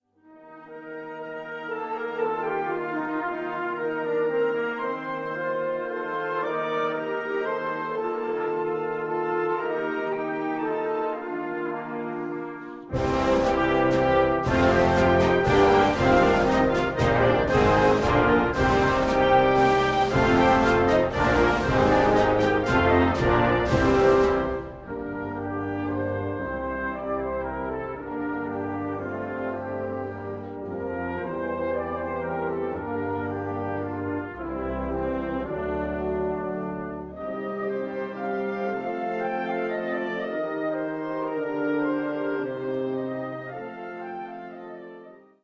Categorie Harmonie/Fanfare/Brass-orkest
Subcategorie Concertmuziek
Bezetting Ha (harmonieorkest)
Perc: SD, BD